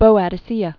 (bōăd-ĭ-sēə)